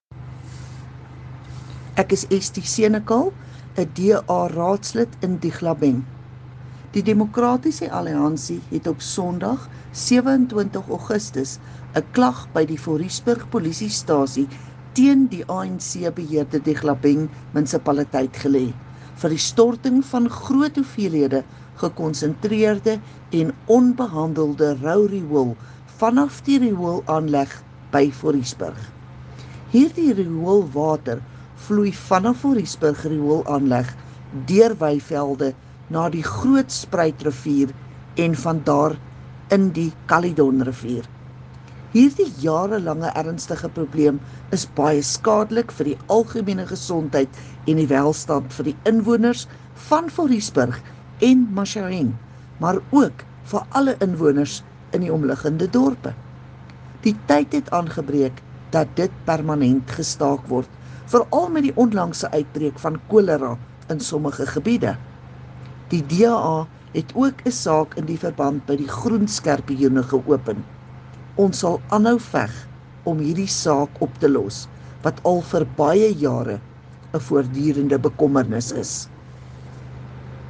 Afrikaans soundbites by Cllr Estie Senekal and